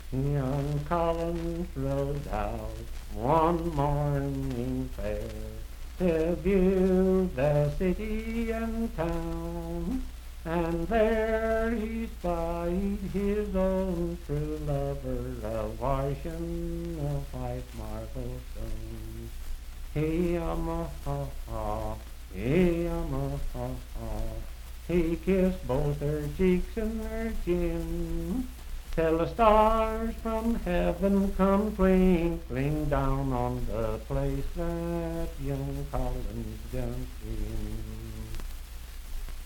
Unaccompanied vocal music
in Dryfork, WV
Voice (sung)